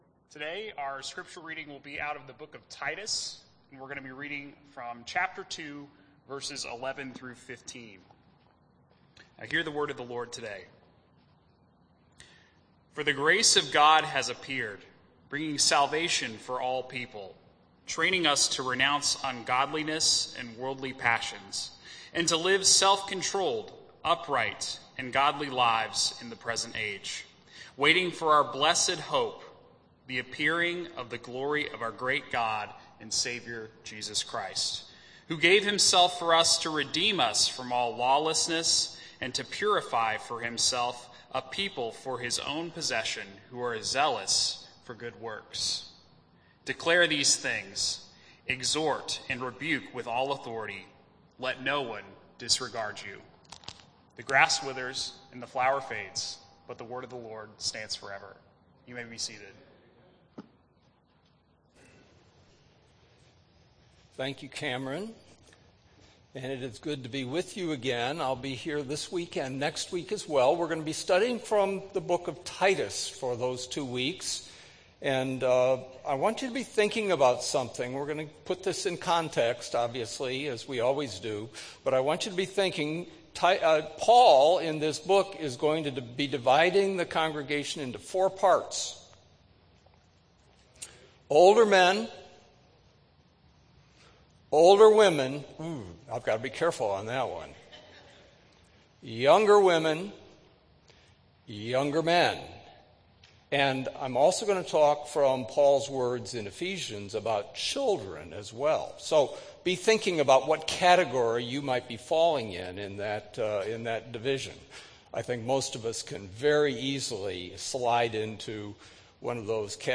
Sunday Morning Worship, May 8, 2022